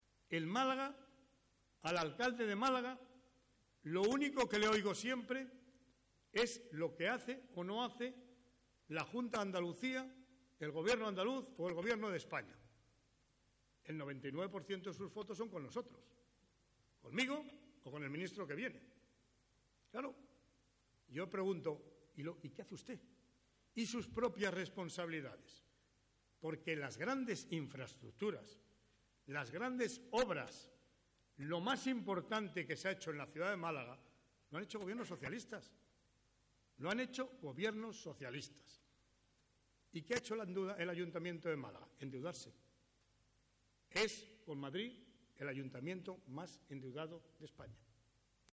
En este sentido, llamó a los parlamentarios, diputados y senadores andaluces, presentes en la Interparlamentaria celebrada en Málaga junto a miembros de la Ejecutiva regional, Consejo de Gobierno y secretarios provinciales, a "utilizar la fuerza" del socialismo para reivindicar más y mejores proyectos para la comunidad andaluza.